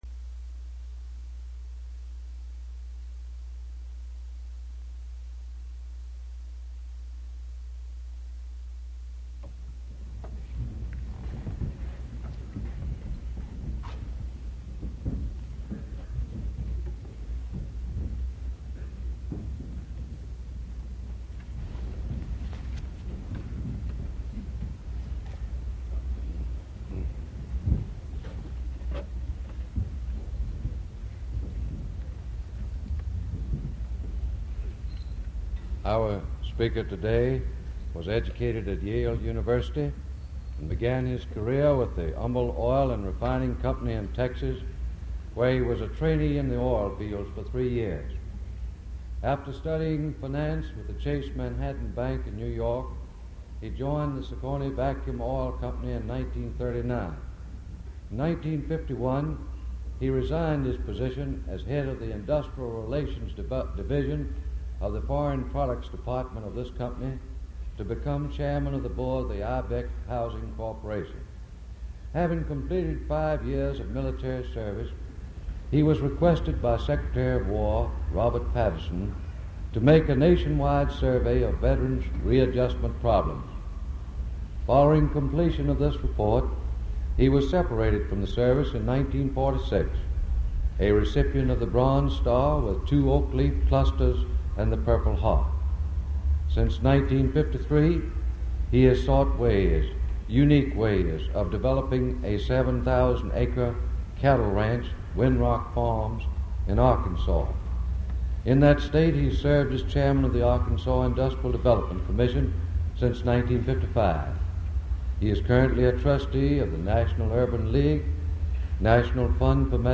Audio Recording of the 1961 Baccalaureate and Commencement Speeches at the College of William and Mary | The W&M Digital Archive